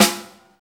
Index of /90_sSampleCDs/Roland L-CD701/SNR_Rim & Stick/SNR_Rim Modules
SNR RATTLE0E.wav